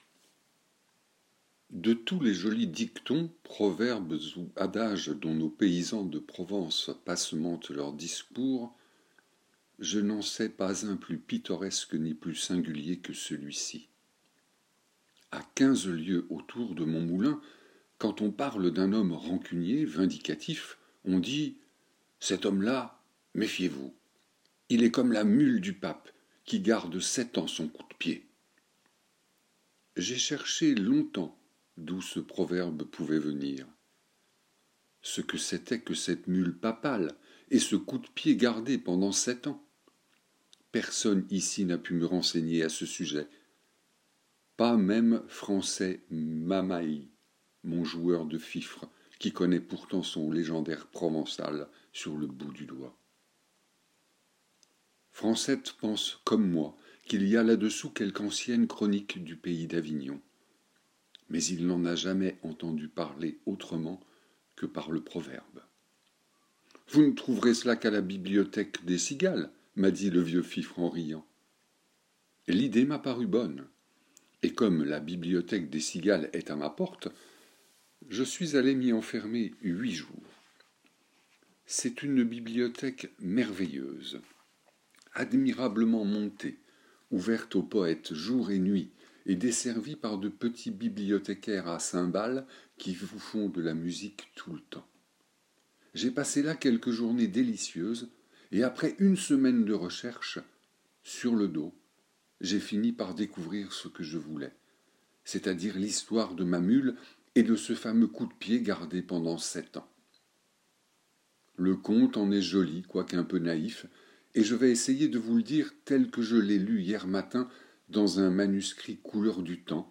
Lecture à écouter - Centre Intercommunal d'Action Sociale du Blaisois